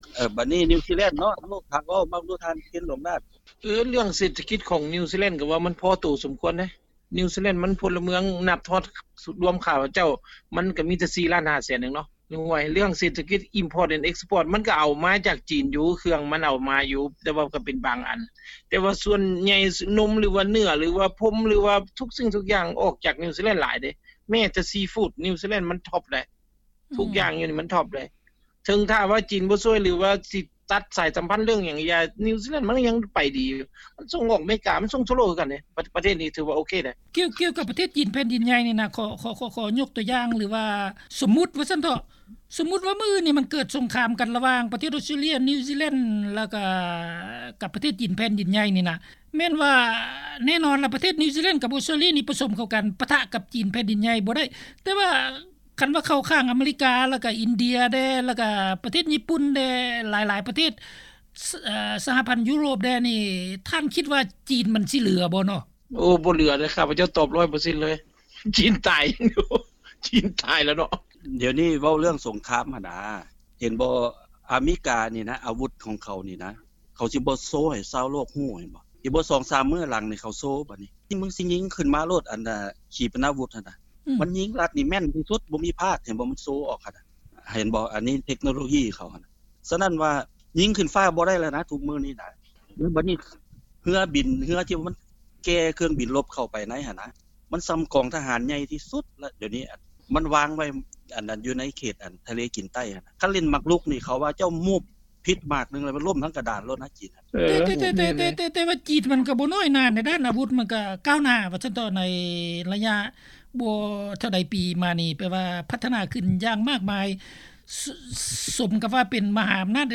for_upload_interview_aus-china_relation_part_2.mp3